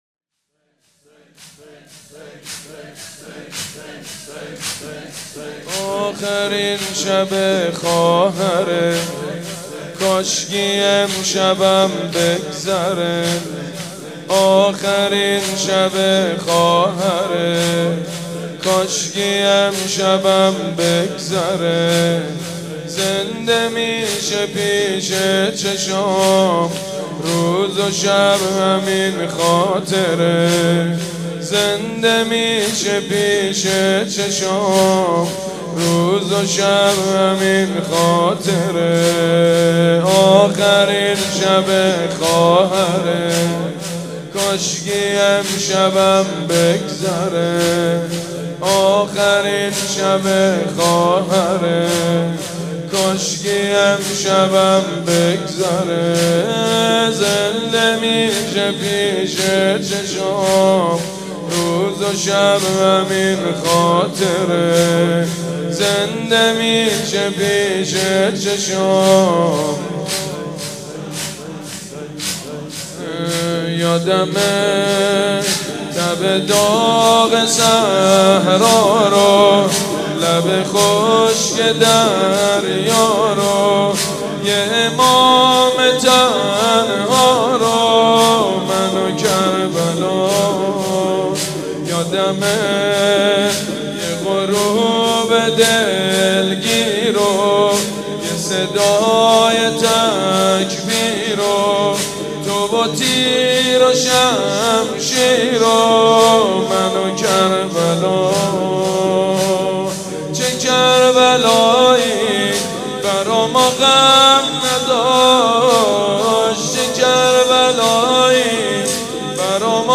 شب شهادت حضرت زینب(س)/ریحانه الحسین(س)
با مرثیه سرایی
۵۲۶۳۵ عقیق:صوت این مجلس را بشنوید.